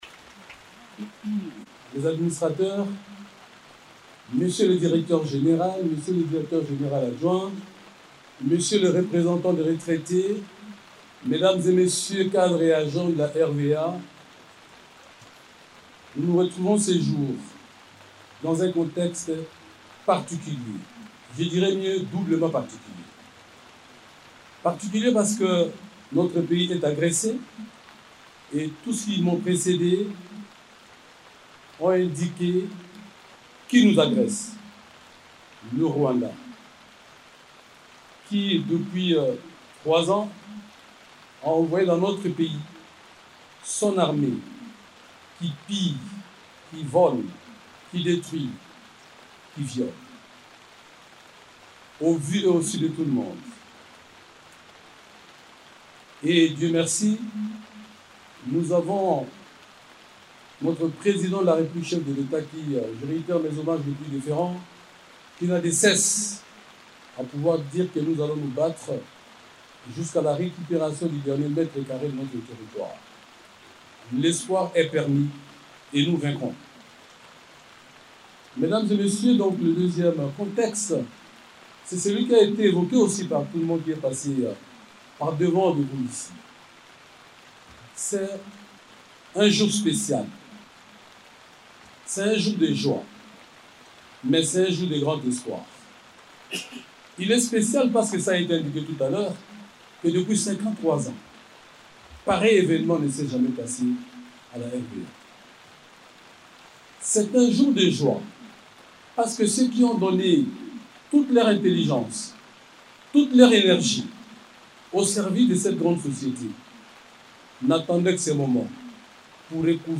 Cette remise symbolique des chèques est intervenue au cours d'une cérémonie présidée par le ministre du Portefeuille, Jean-Lucien Bussa, représentant personnel de la Première ministre dans l’enceinte du siège de la Direction générale de la RVA.
Suivez en intégralité le discours du ministre Jean-Lucien Bussa: